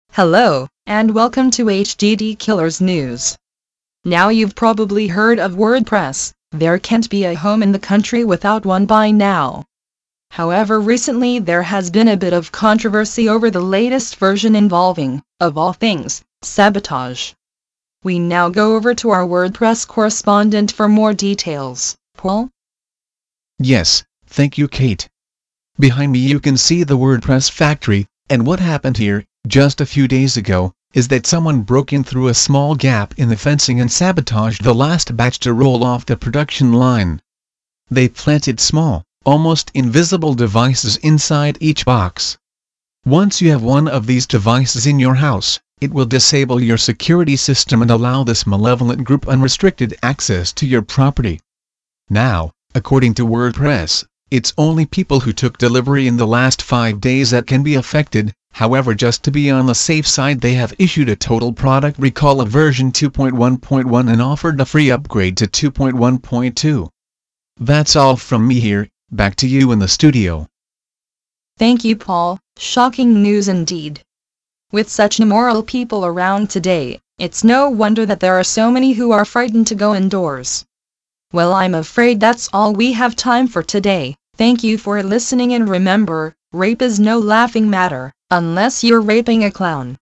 Two new voices actually, one called Kate and one called Paul, both from NeoSpeech. I installed them a few days ago, and they sound remarkably realistic. Not the 1960’s-sci-fi-film-esque voices we’re all used to, but … almost human. In my opinion, Paul’s voice is the slightly better one, but you can decide for yourself, I’ve created an audio file of this blog post, read out to you by Paul and Kate news caster style, and with a slight twist.
It still has some rough edges but overall it sounds very realistic.
Yeah, I think they pause for just slightly too long between words though, and increasing the speed to +1 just makes it seem a bit rushed.